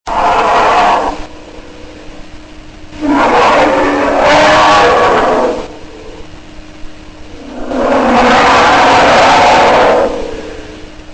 Orso Bruno Marsicano
Ascolta il ruggito dell'orso (MP3 88 kb)
orso.mp3